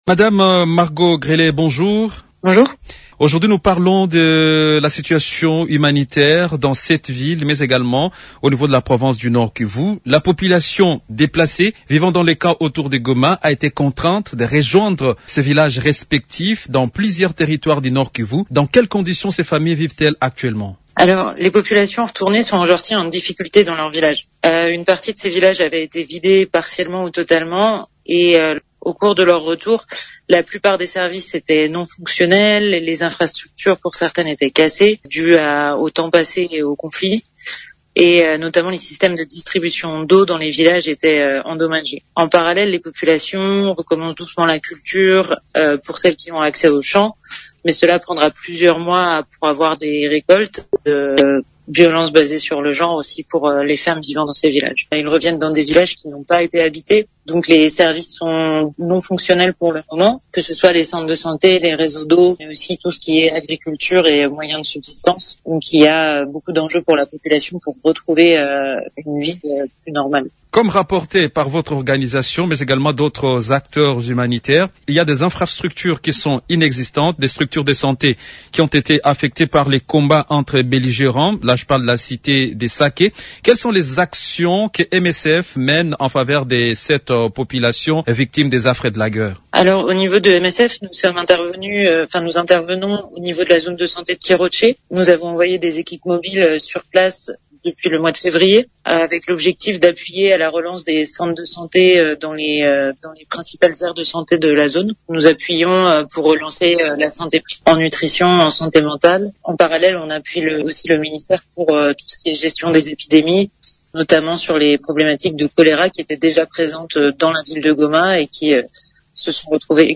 Invitée de Radio Okapi ce jeudi 27 mars